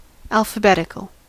Ääntäminen
IPA : /ˌælf.əˈbɛt.ɪk.əl/ GenAM: IPA : /ˌælfəˈbɛdɪkəl/